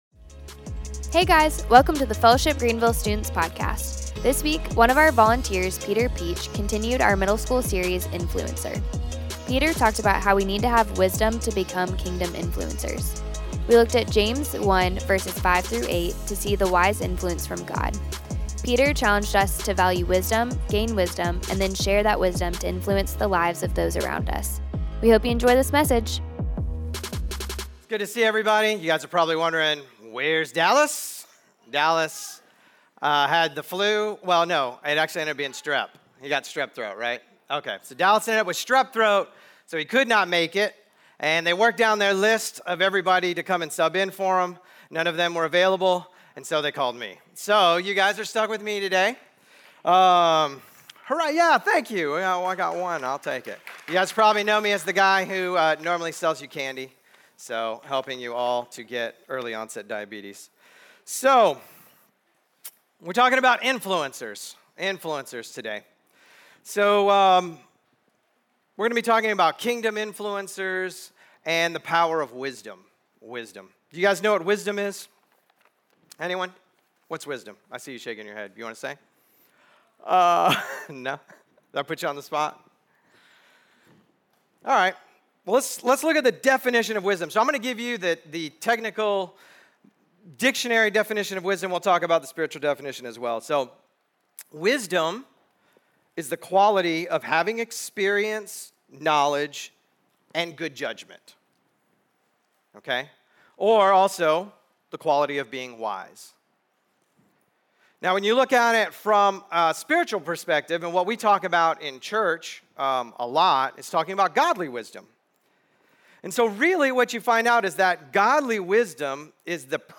We hope you enjoy this message!